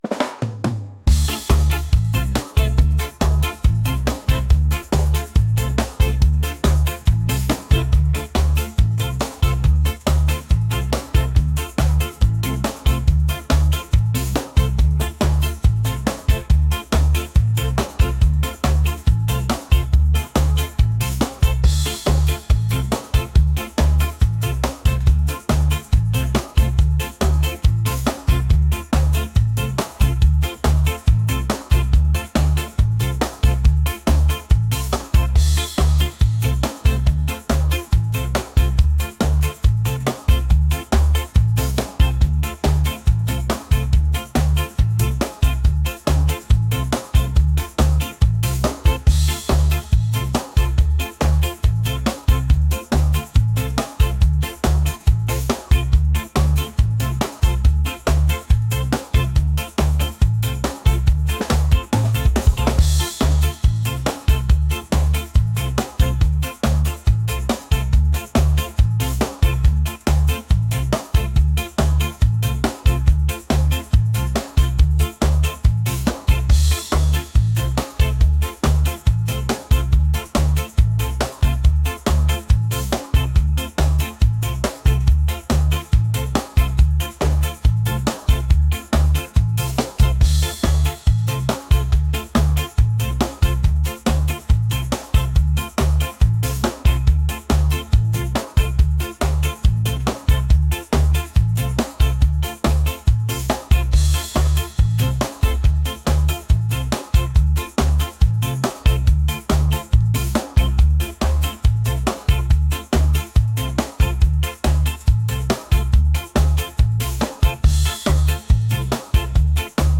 reggae | upbeat | energetic